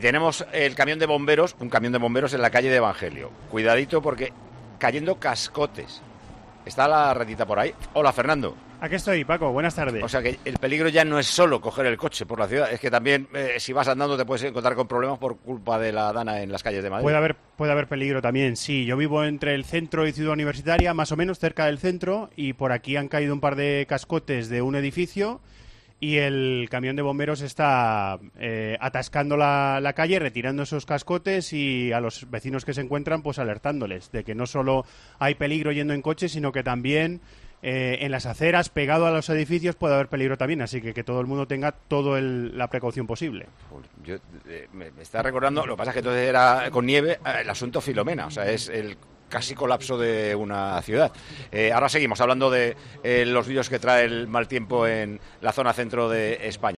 Escucha este fragmento de Tiempo de Juego en el que se narra el escenario que se está viviendo con las lluvias.